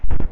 Changed Tree Hit Audio
TreeHit.wav